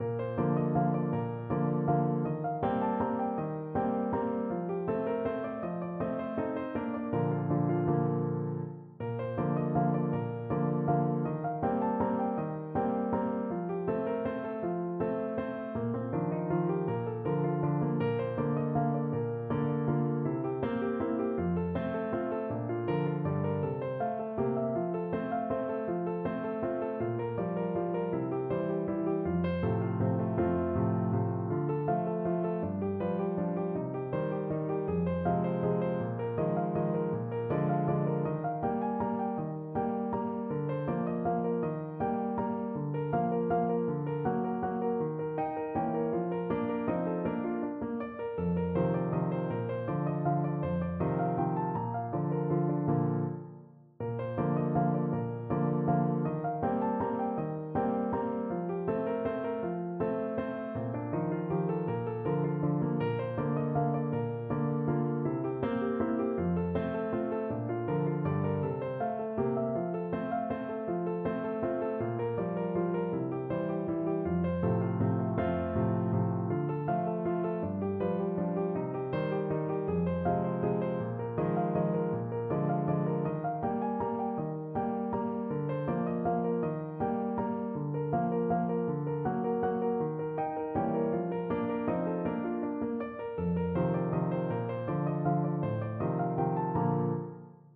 Classical Mozart, Wolfgang Amadeus Deh, vieni from Don Giovanni Trumpet version
Play (or use space bar on your keyboard) Pause Music Playalong - Piano Accompaniment Playalong Band Accompaniment not yet available transpose reset tempo print settings full screen
Trumpet
6/8 (View more 6/8 Music)
= 80 Allegretto
Bb major (Sounding Pitch) C major (Trumpet in Bb) (View more Bb major Music for Trumpet )
Classical (View more Classical Trumpet Music)